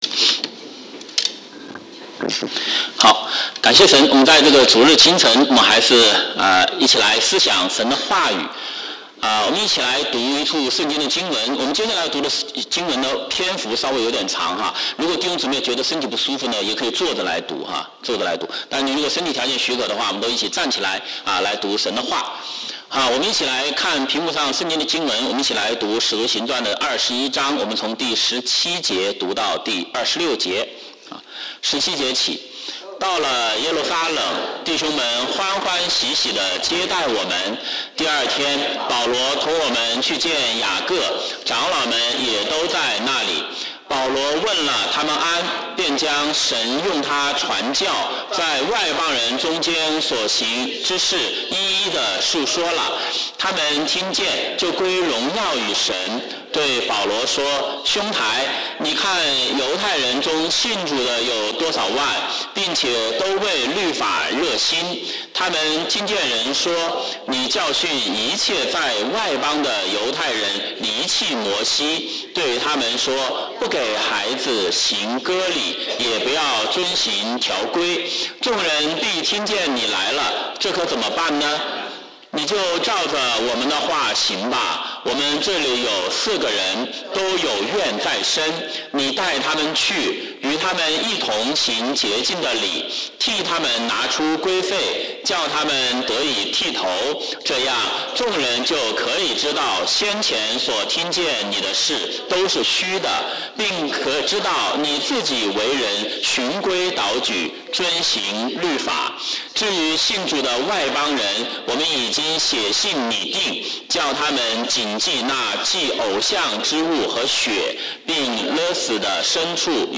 華語主日崇拜講道錄音